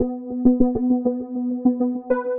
描述：寒冷的合成器转轮，中等节奏。
Tag: 100 bpm Chill Out Loops Synth Loops 413.61 KB wav Key : B